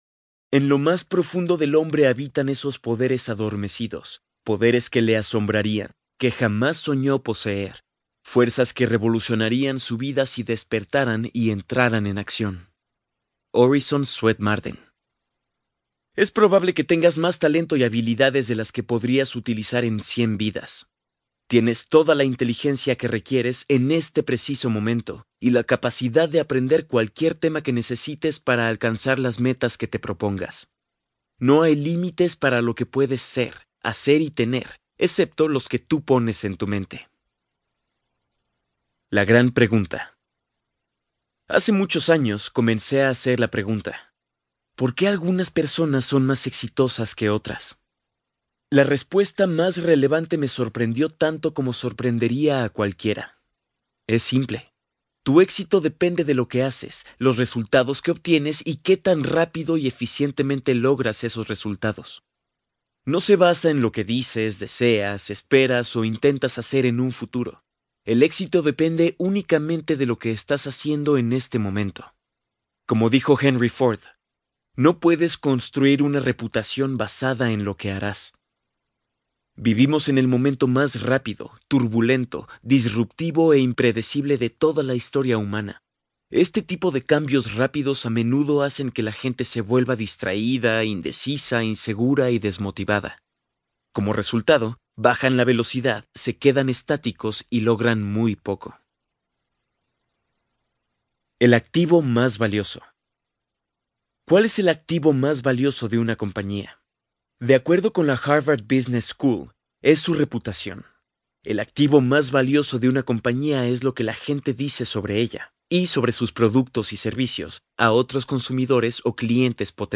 👇 MIRA LA LISTA COMPLETA Y ESCUCHA LA MUESTRA DE CADA AUDIOLiBRO 👇